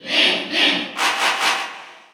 File:Link & Toon Link Cheer Russian SSBU.ogg
Category: Crowd cheers (SSBU)
Link_&_Toon_Link_Cheer_Russian_SSBU.ogg